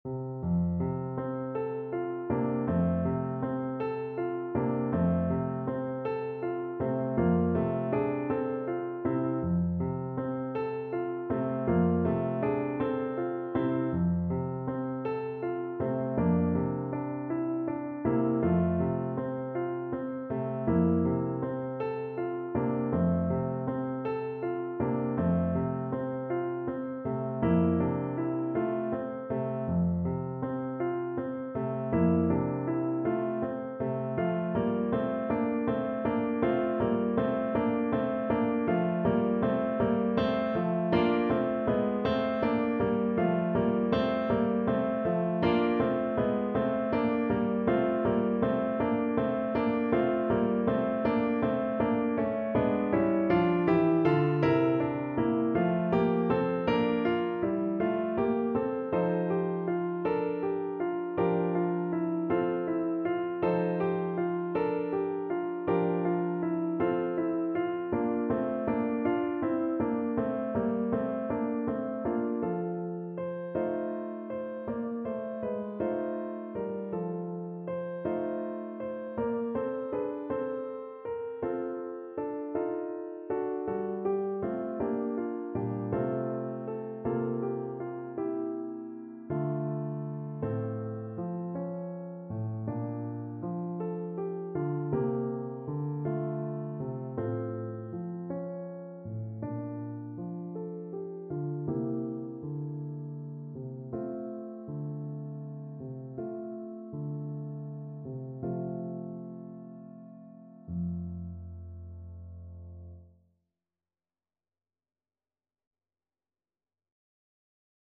Free Sheet music for French Horn
Play (or use space bar on your keyboard) Pause Music Playalong - Piano Accompaniment Playalong Band Accompaniment not yet available transpose reset tempo print settings full screen
French Horn
E4-F5
F major (Sounding Pitch) C major (French Horn in F) (View more F major Music for French Horn )
6/8 (View more 6/8 Music)
Andante
Traditional (View more Traditional French Horn Music)